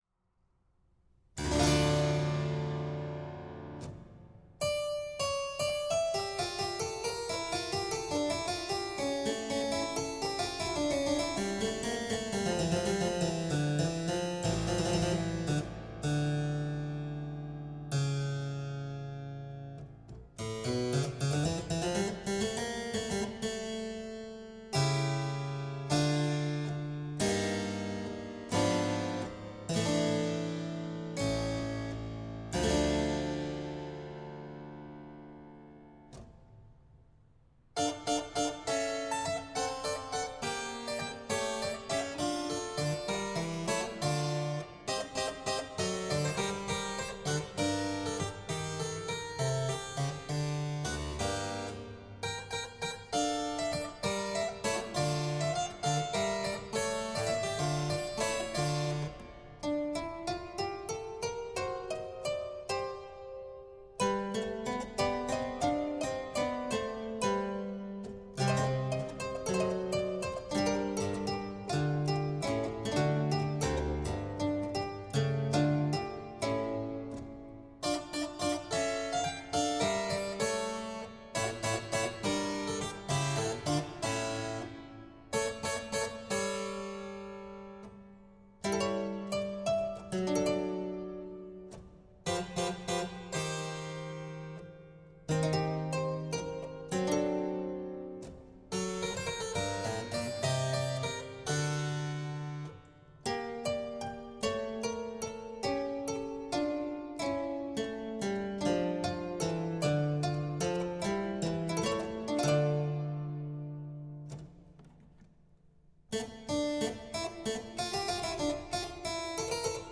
Improvisierte Fantasie für Cembalo
Cembalo
19-Fantasia-in-d-1.mp3